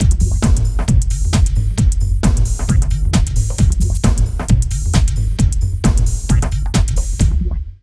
80 LOOP   -L.wav